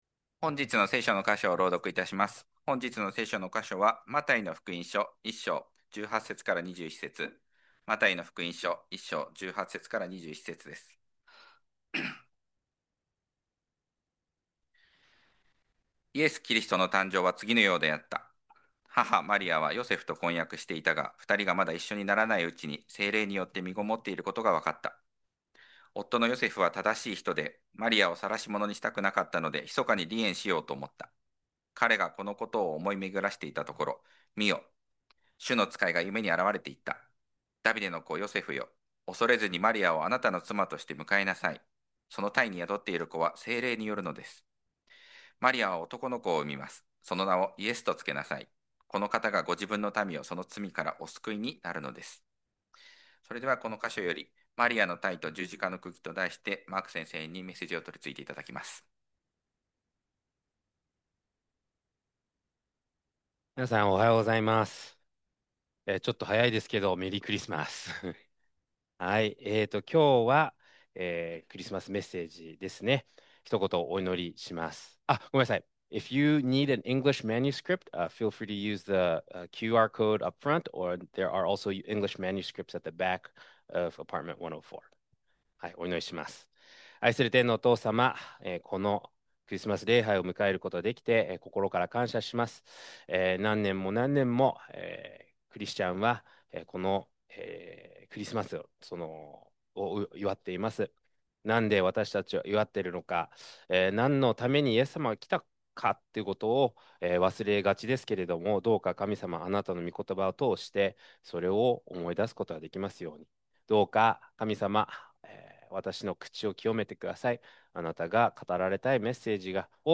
礼拝式順